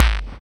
1710L BD.wav